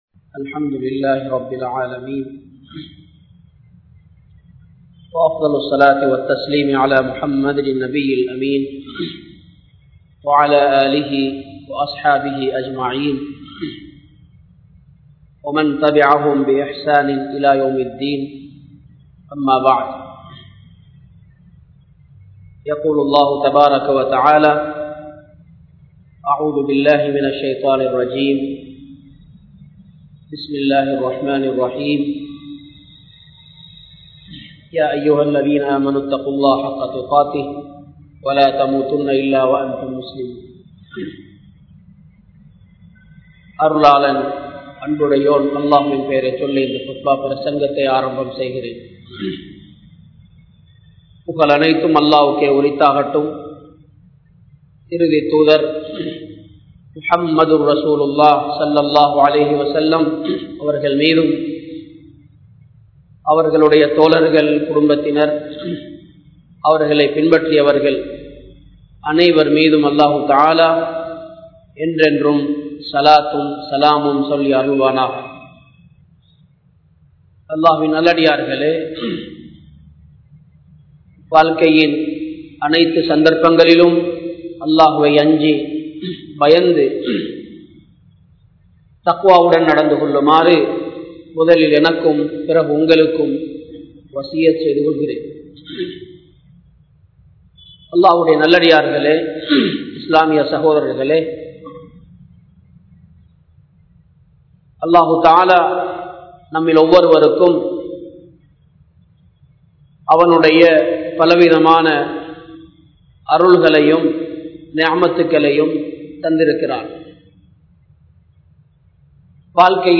Maranam | Audio Bayans | All Ceylon Muslim Youth Community | Addalaichenai
Borella Jumua Masjith